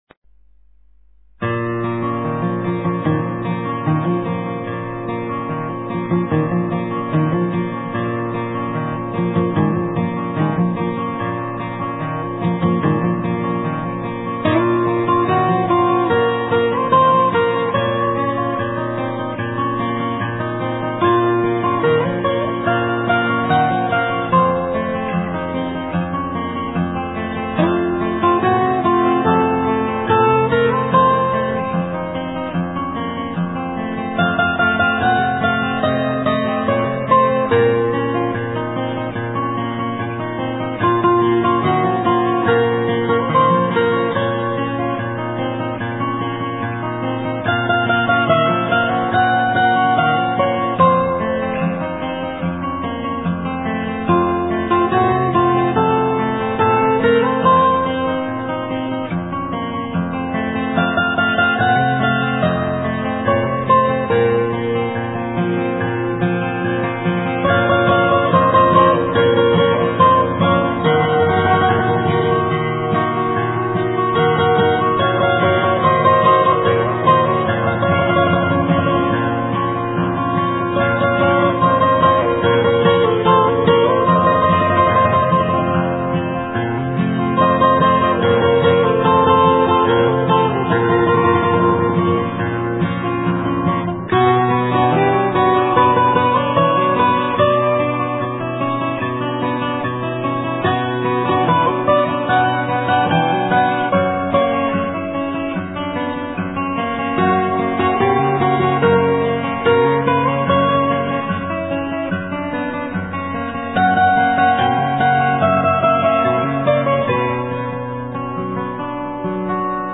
* Thể loại: Ngoại Quốc